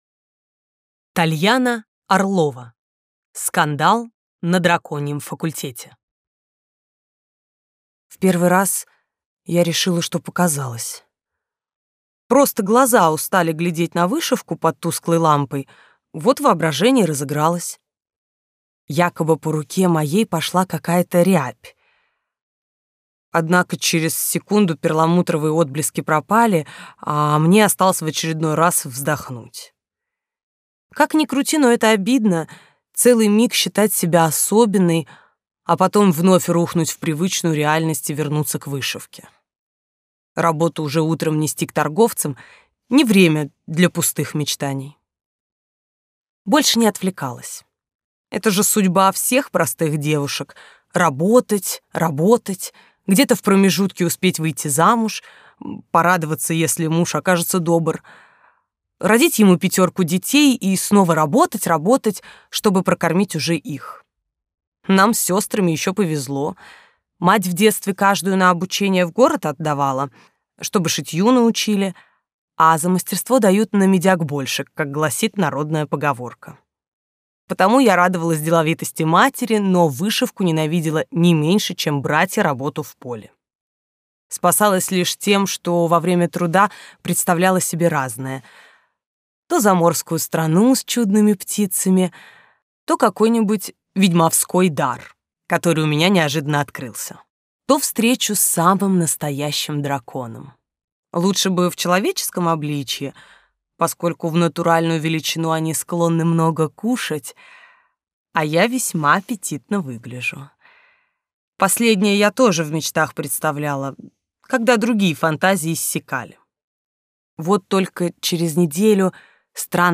Аудиокнига Скандал на драконьем факультете | Библиотека аудиокниг